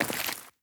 Glass_Grit_Mono_03.wav